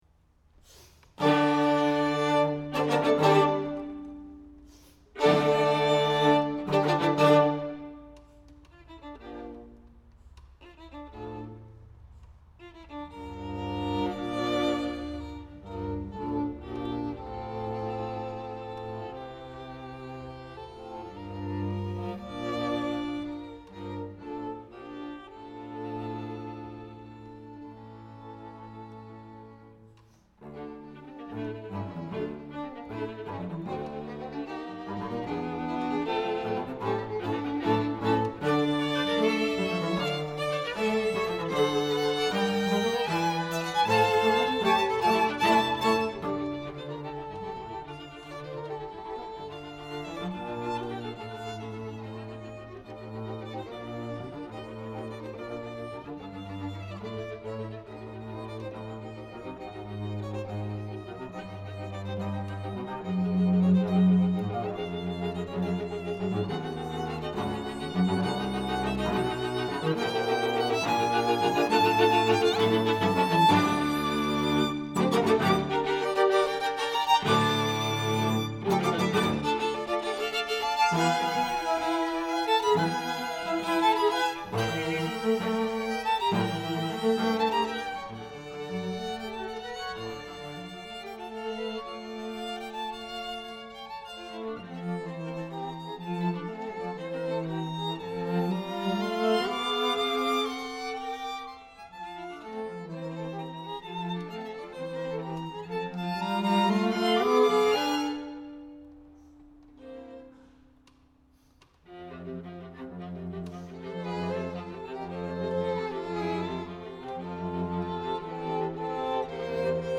Chamber Groups
Allegro